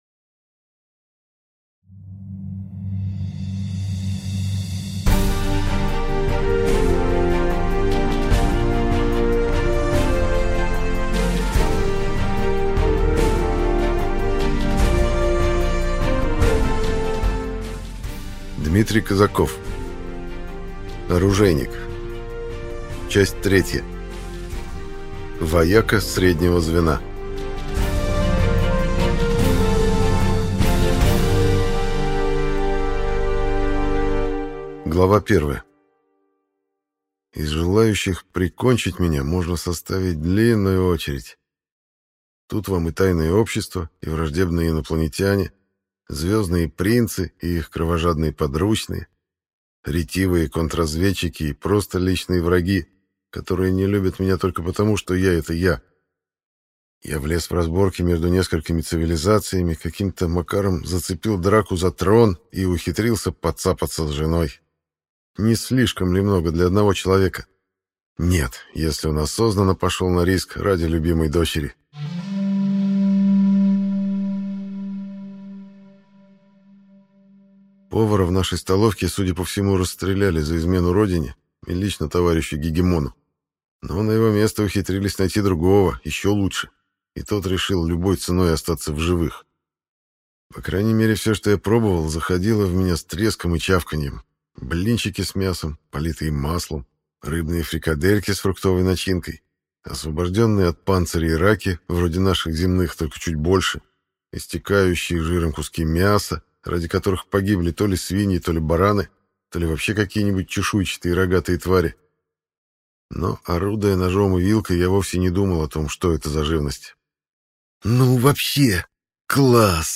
Аудиокнига Вояка среднего звена | Библиотека аудиокниг